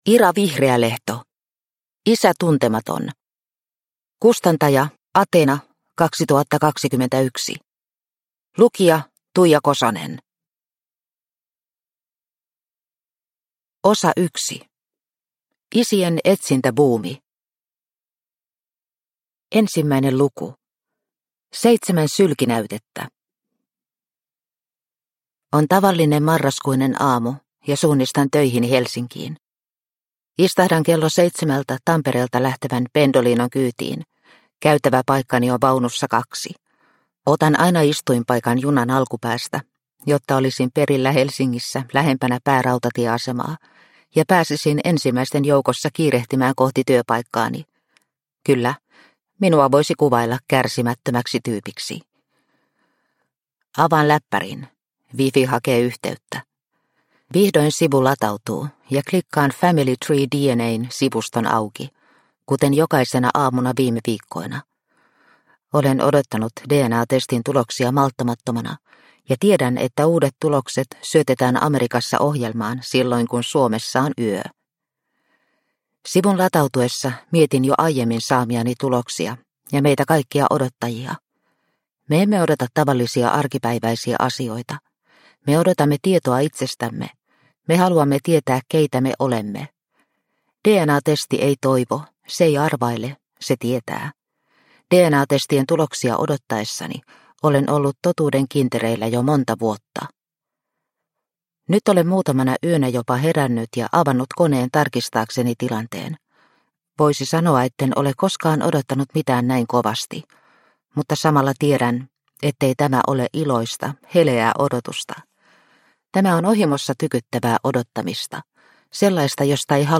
Isä tuntematon – Ljudbok – Laddas ner